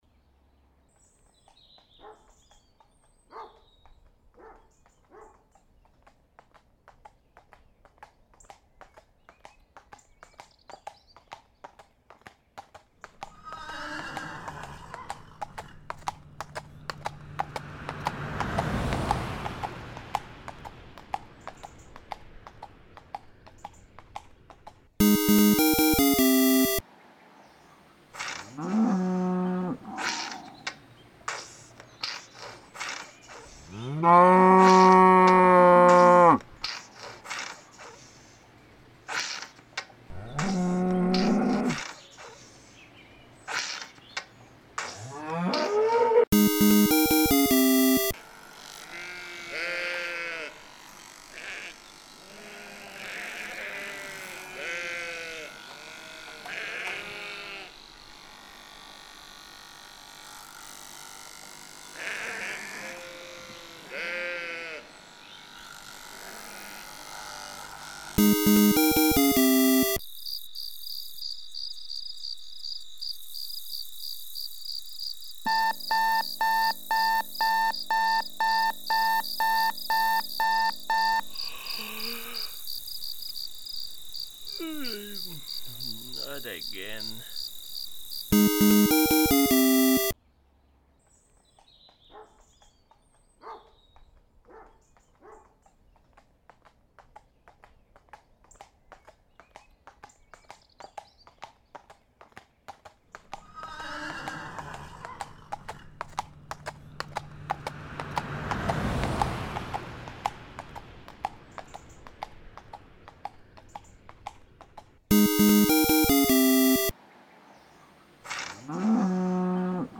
Sound design is fun!